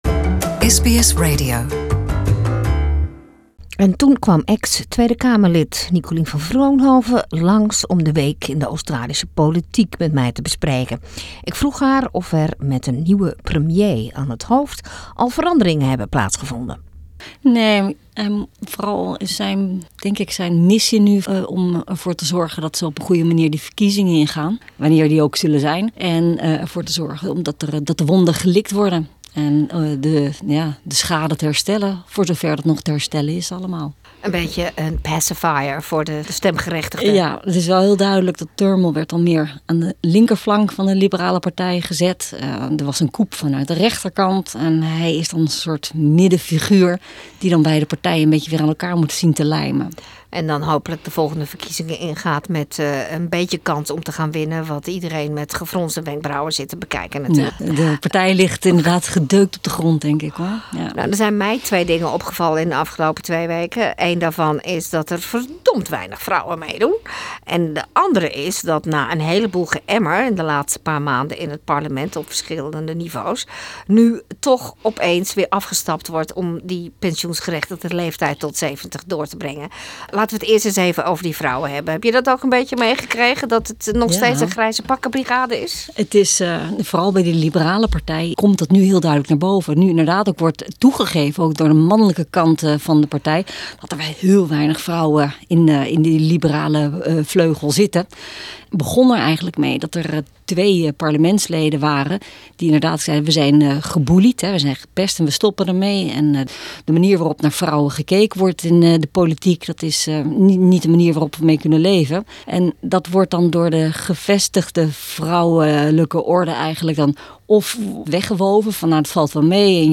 Special reporter for Political Affairs, Dutch ex-parliamentarian Nicolien van Vroonhoven, sheds light on Scomo, solar panels and empty election promises this week.